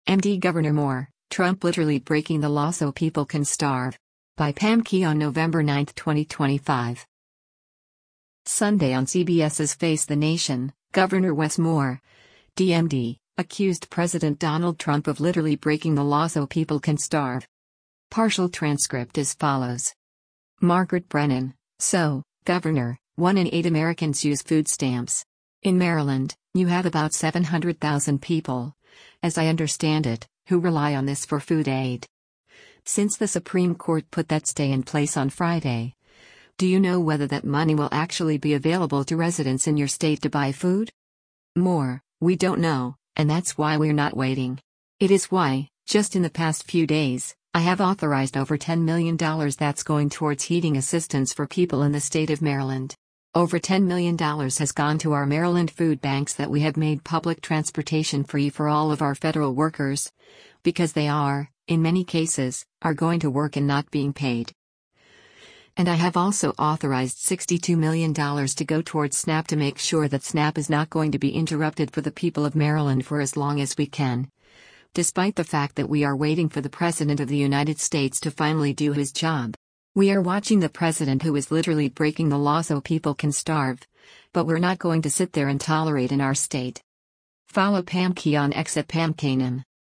Sunday on CBS’s “Face the Nation,” Gov. Wes Moore (D-MD) accused President Donald Trump of “literally breaking the law so people can starve.”